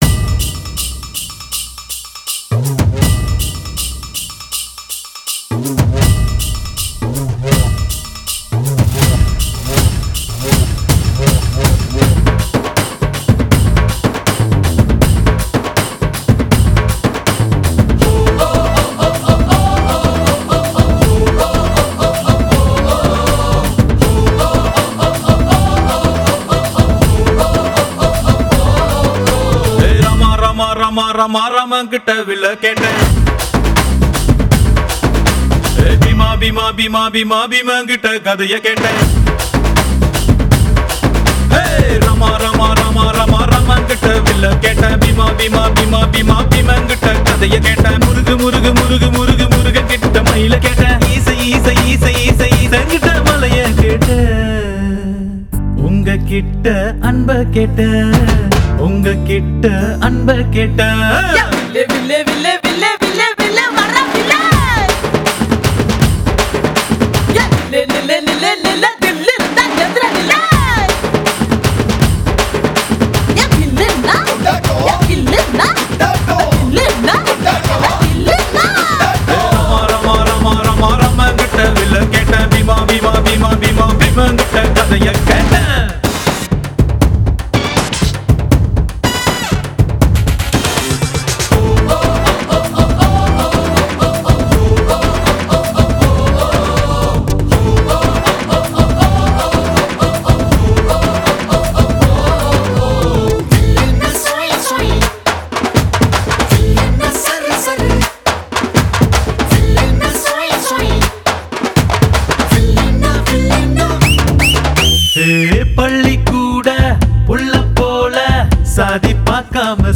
Category: DJ AND BAND (BEND) ADIVASI REMIX